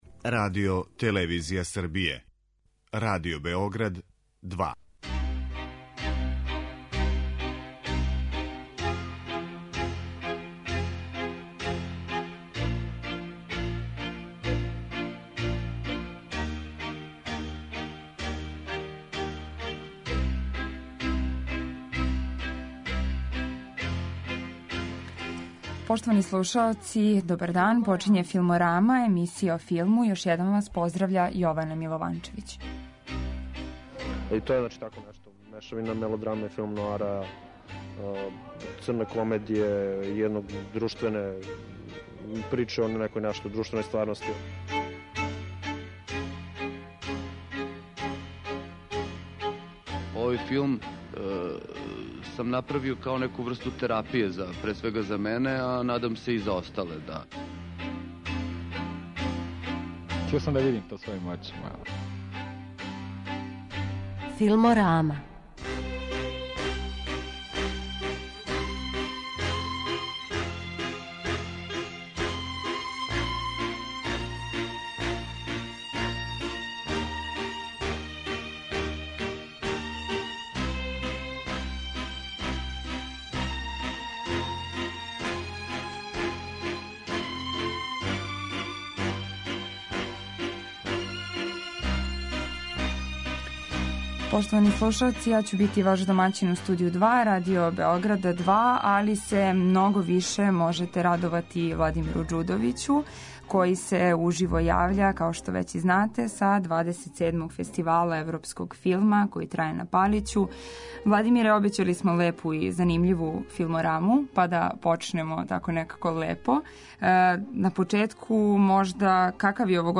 Уживо са Палића: 27. Фестивал европског филма